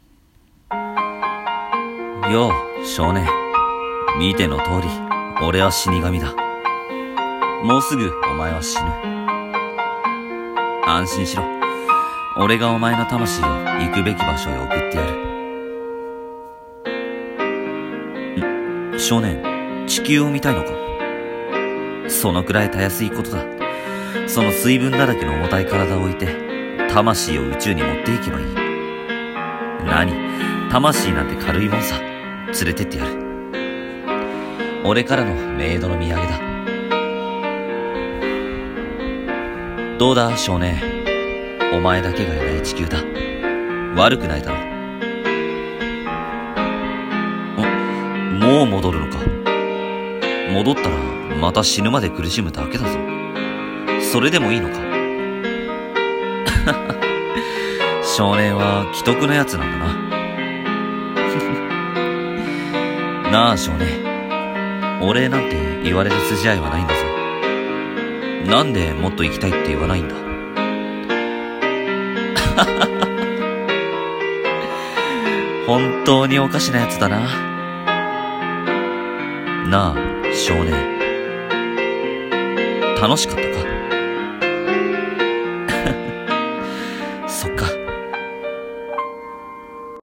声劇「少年と死神」